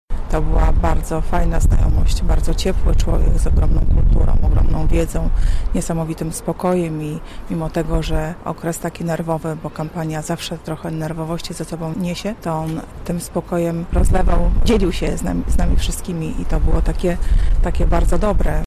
O tym jakim był człowiekiem, mówi Beata Mazurek, rzecznik prasowy PiS